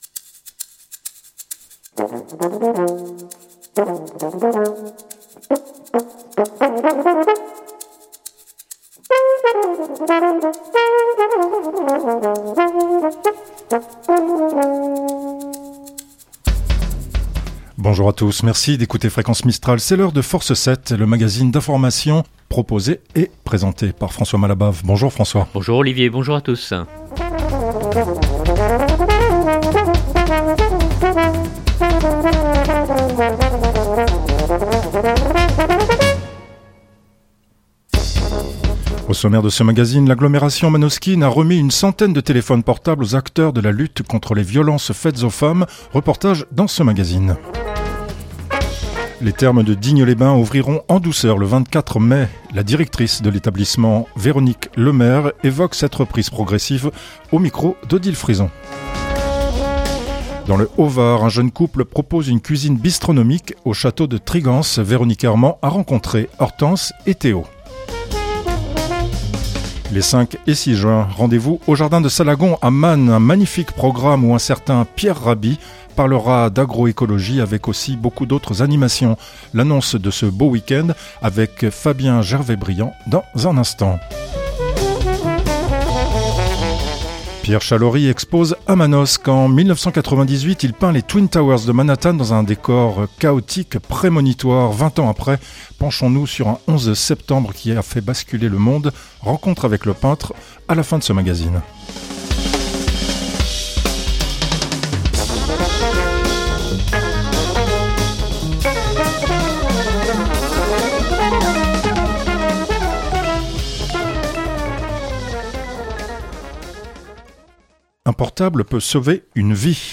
Au sommaire : L’agglomération manosquine a remis une centaine de téléphones portables aux acteurs de la lutte contre les violences faites aux femmes. Reportage dans ce magazine. Les thermes de Digne-les-Bains ouvriront en douceur le 24 mai.